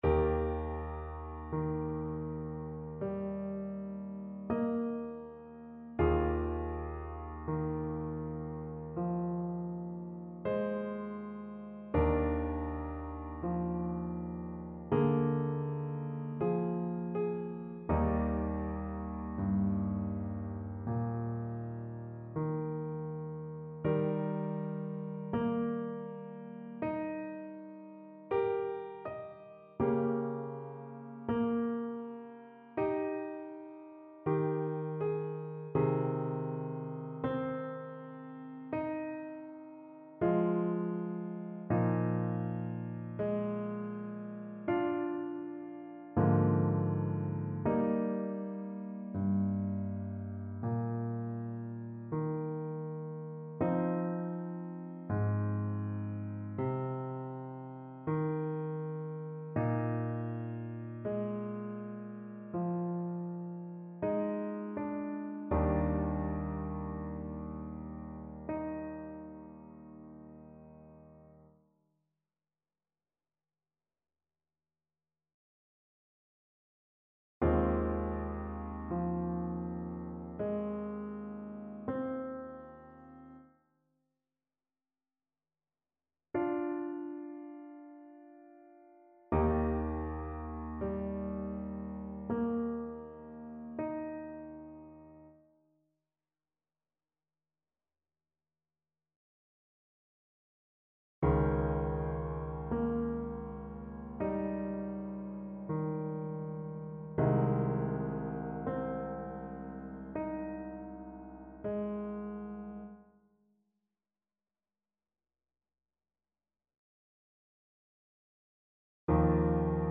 =56 Trs lent
4/4 (View more 4/4 Music)
Classical (View more Classical Soprano Voice Music)